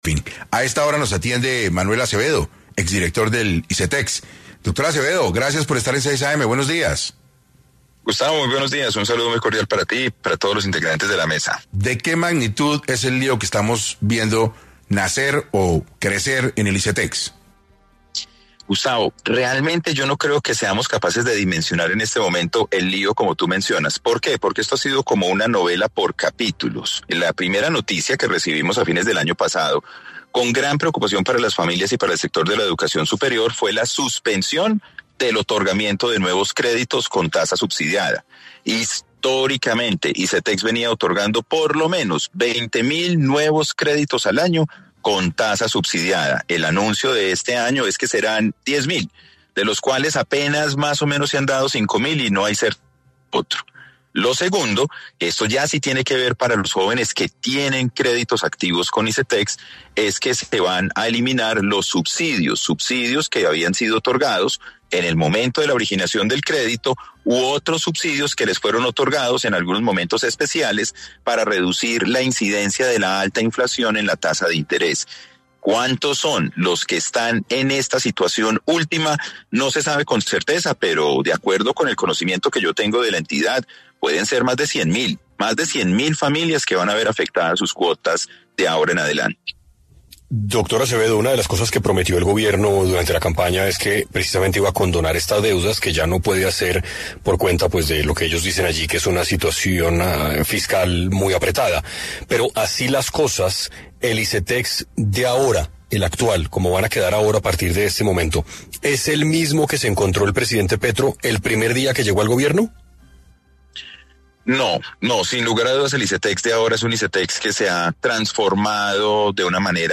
En 6 AM el exdirector del ICETEX Manuel Acevedo, se refiere a la situación que viven estudiantes y familias por la falta de subsidios y recursos que debería estar entregando la entidad.